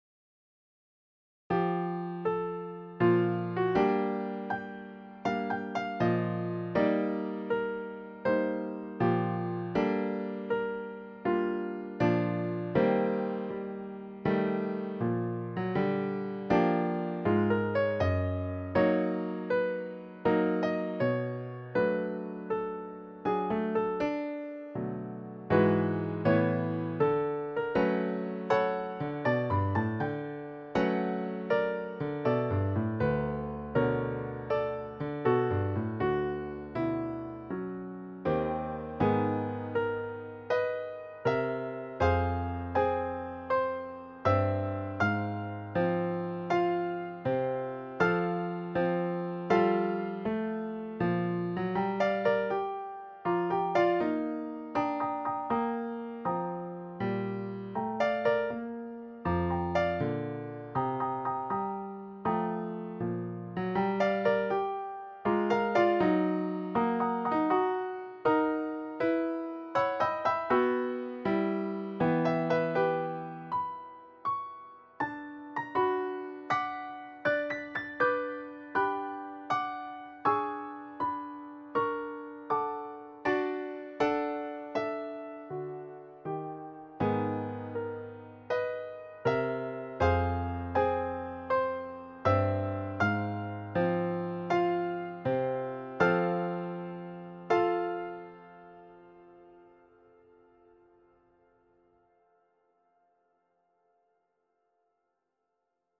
Recently I decided I wanted to get decent recordings of my thesis compositions, so I have been recording them using GarageBand.